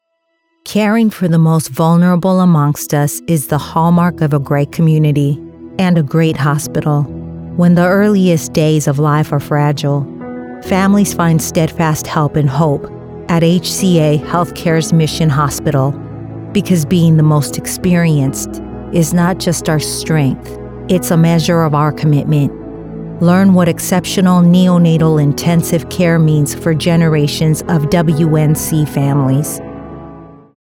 Explainer & Whiteboard Video Voice Overs
Adult (30-50)